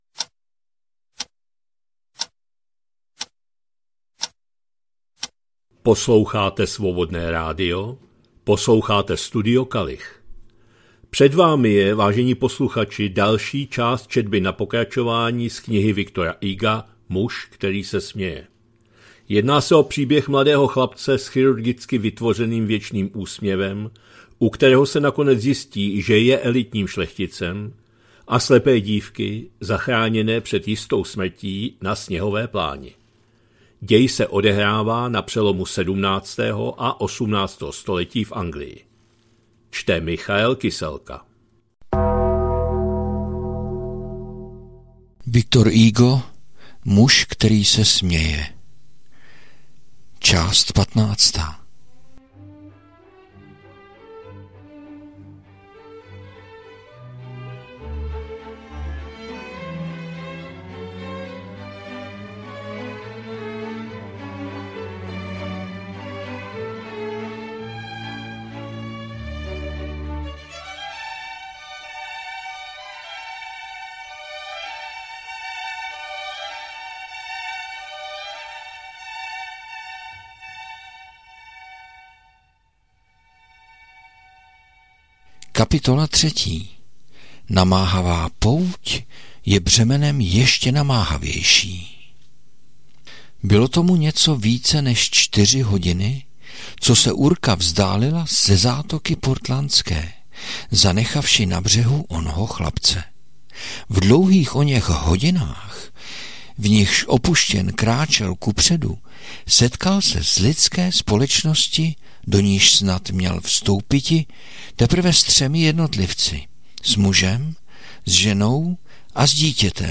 2025-06-16 – Studio Kalich – Muž který se směje, V. Hugo, část 15., četba na pokračování